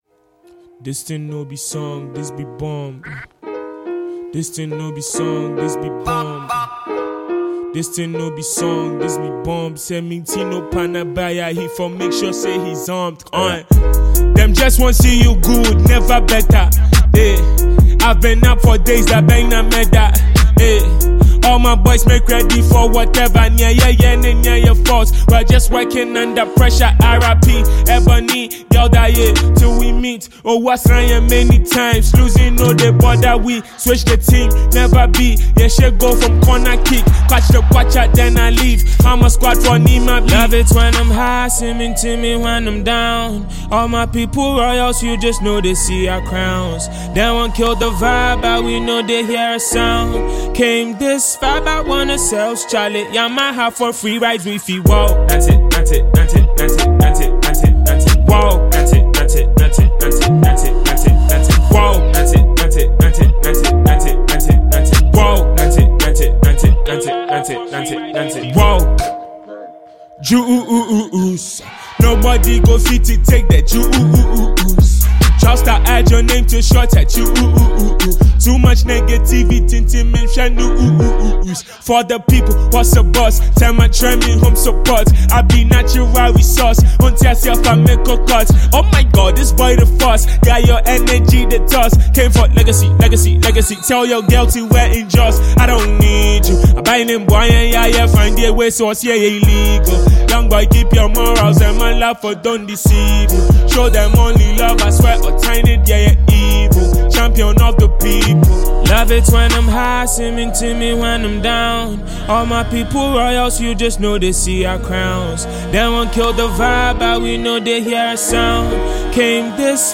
Ghanaian rapper
South African Rapper